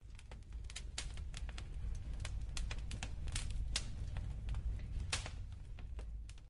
campfire_small.0.ogg